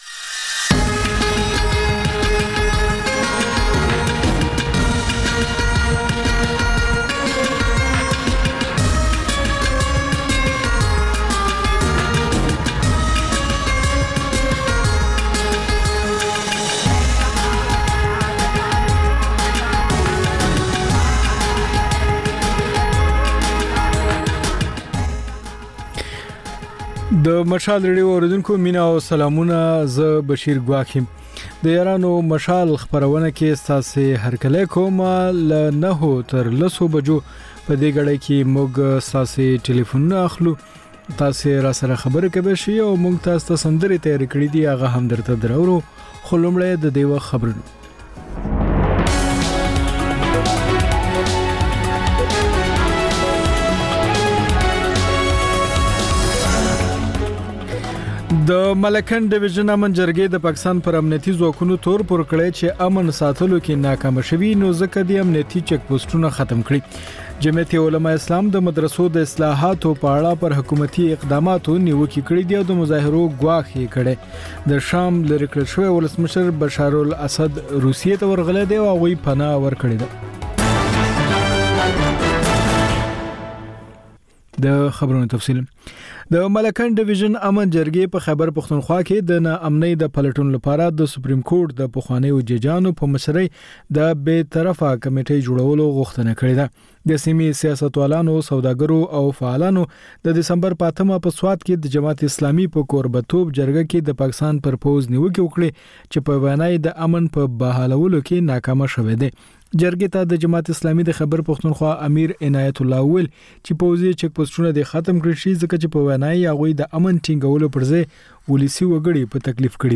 د یارانو مشال په ژوندۍ خپرونه کې له اورېدونکو سره بنډار لرو او سندرې خپروو.